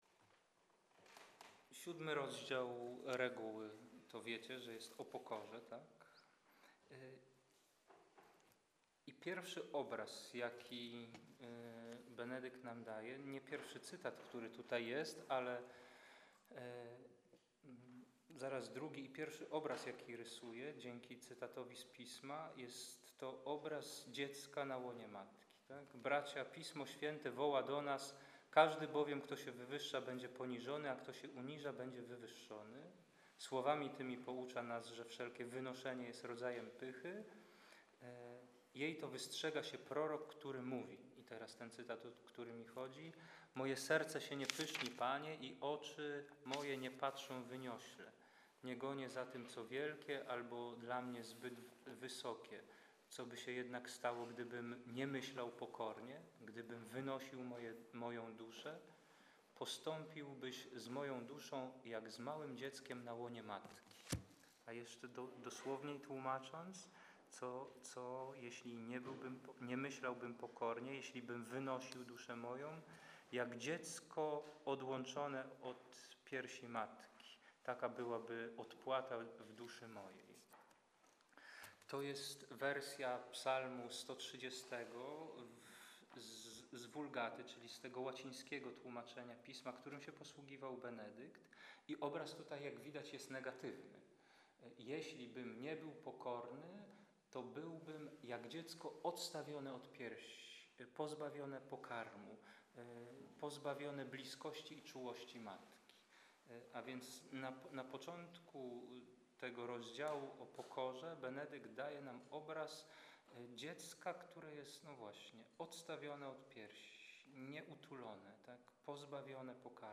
konferencja wygłoszona podczas spotkania oblatów warszawskiego klasztoru benedyktynek sakramentek.